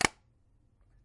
铁门4800 24bit
标签： 金属 金属
声道立体声